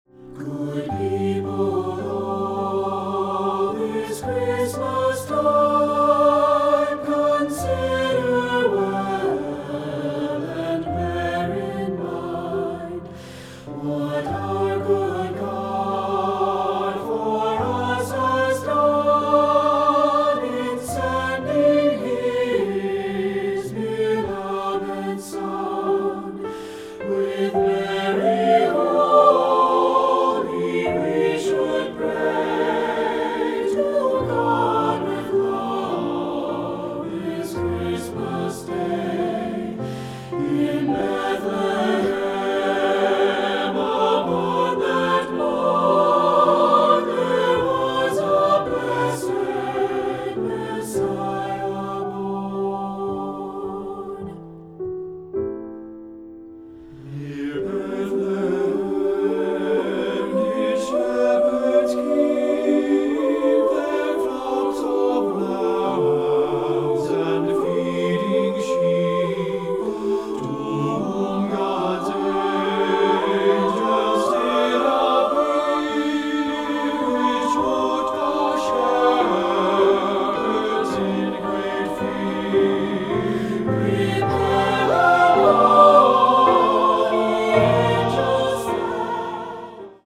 Choral Christmas/Hanukkah
Irish Carol
SATB